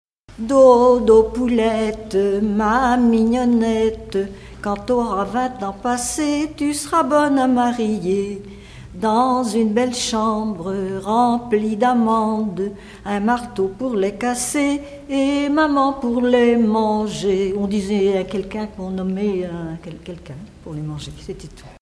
enfantine : berceuse
Pièce musicale inédite